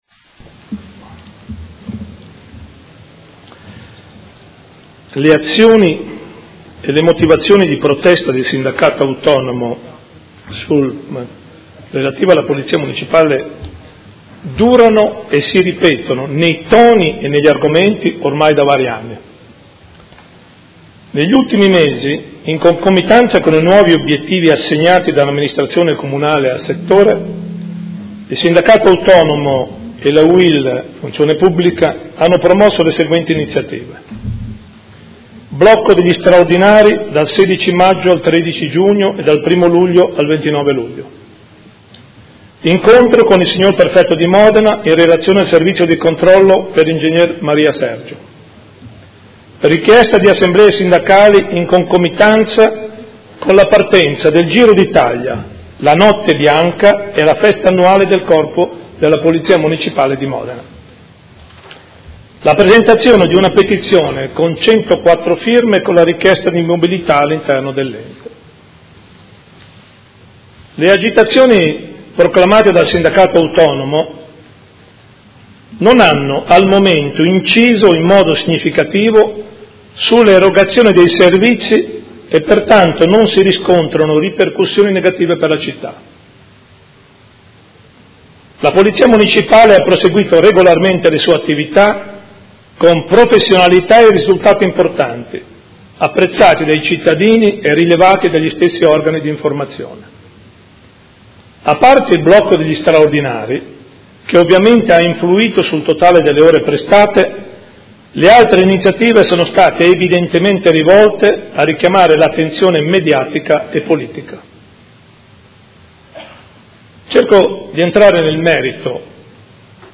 Seduta del 7 luglio. Interrogazione del Gruppo Movimento Cinque Stelle avente per oggetto: Chiarezza sulla situazione all’interno del Corpo di Polizia Municipale. Risponde il Sindaco